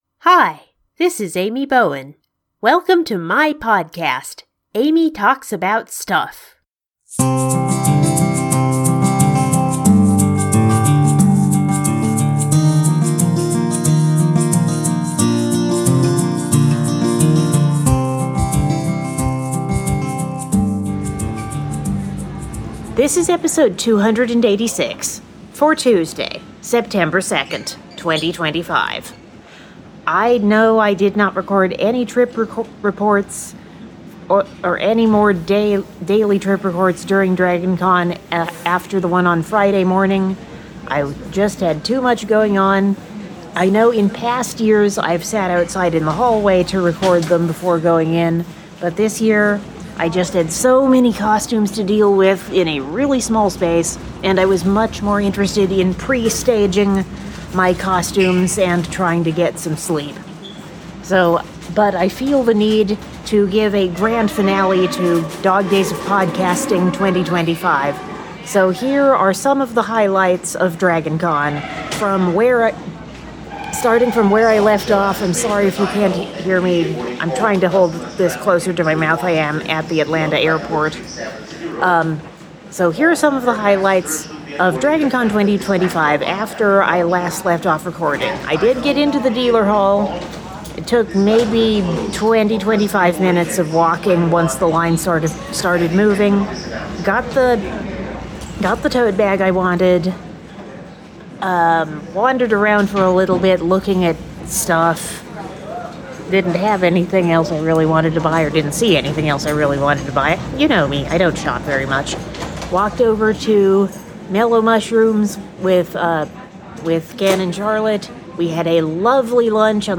Covering Friday afternoon through Monday evening. Sorry about the Auphonic gaps.